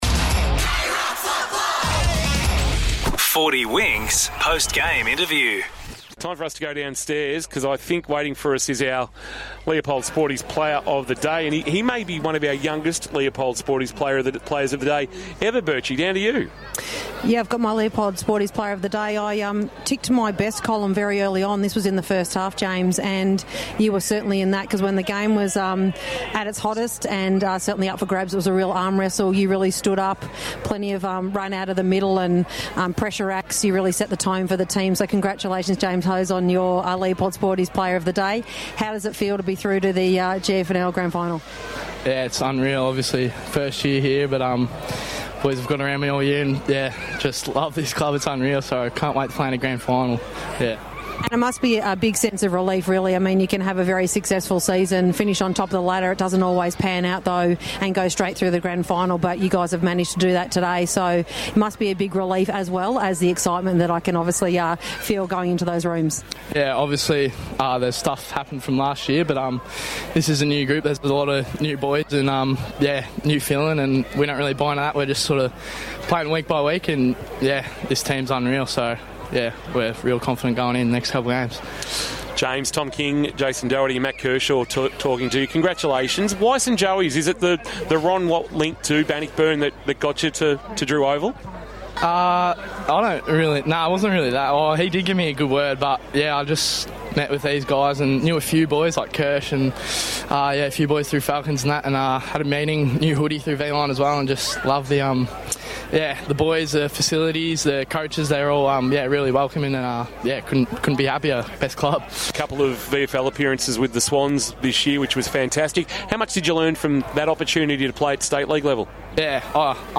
2025 - GFNL - Second Semi-Final - St Joseph's vs. Colac - Post-match interview